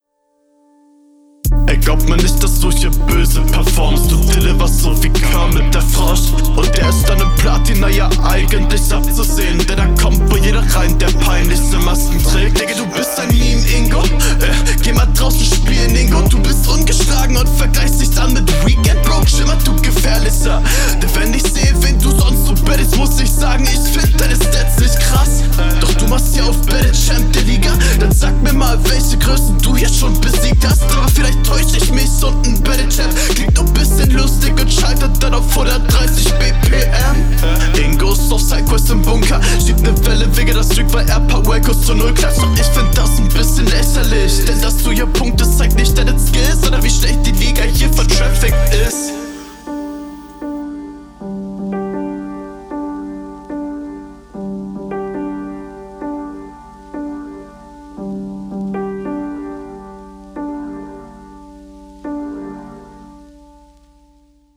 Flow stabil, Mix nicht ausgereift, Inhalt ganz gut, schön von oben herab, Reime eher schwach
Chillige Flows, gute Technik, angenehmer Mix und Gegnerbezug ist auch da.